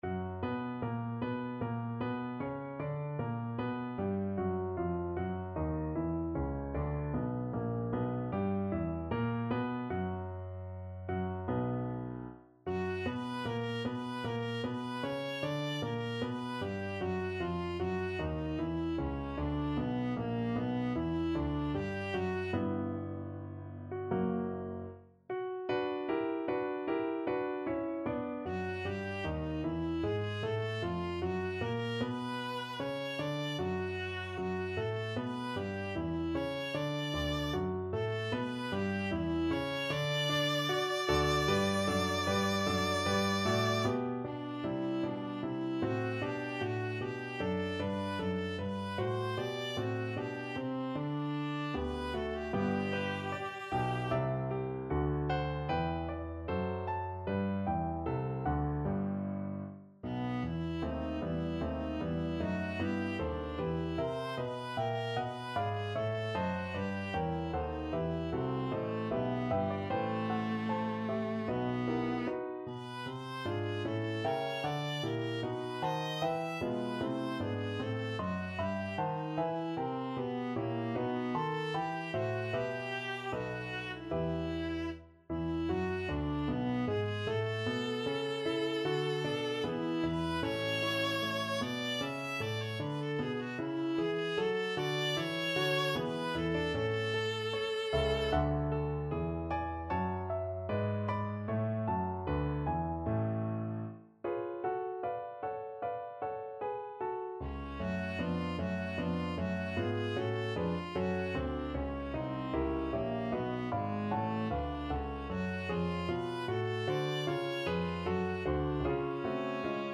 Viola
B minor (Sounding Pitch) (View more B minor Music for Viola )
4/4 (View more 4/4 Music)
Larghetto (=76)
F#4-E6
Classical (View more Classical Viola Music)